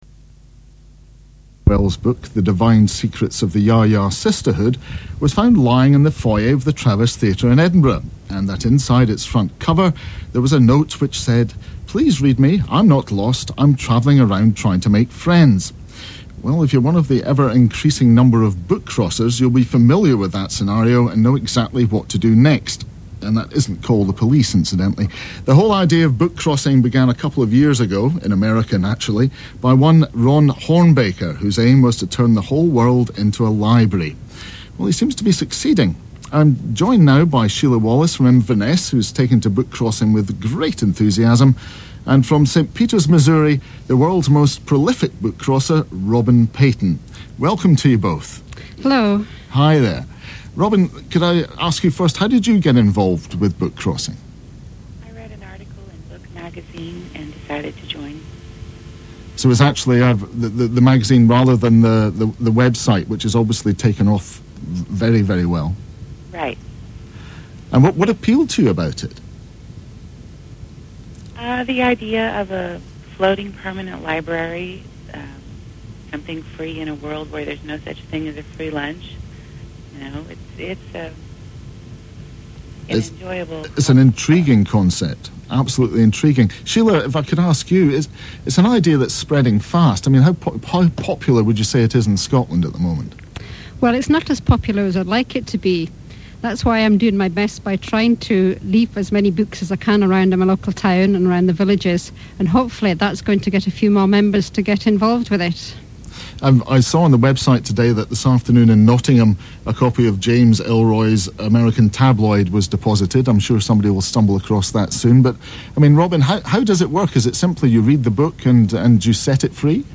Bookcrossing Radio Scotland interview